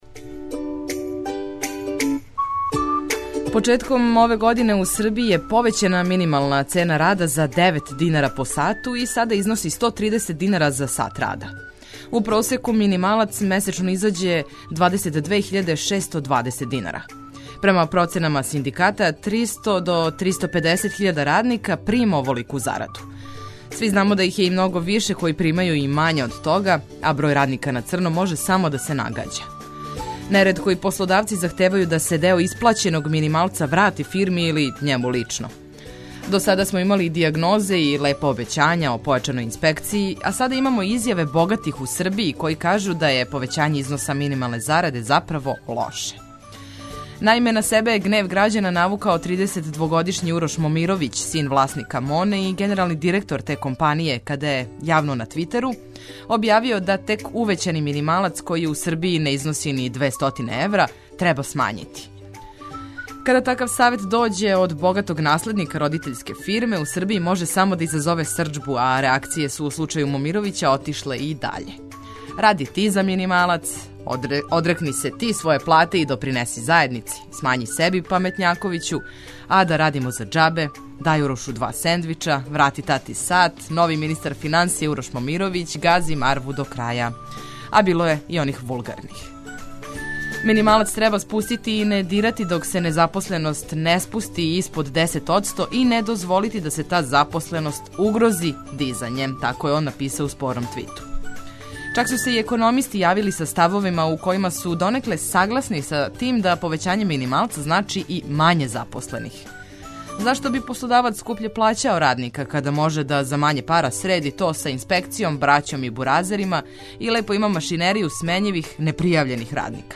Нови дан дочекујемо уз осмех, много корисних и важних информација, а јутро улепшавамо и музиком уз коју ћете сигурно лакше да се разбудите, певушећи у сусрет свим обавезама.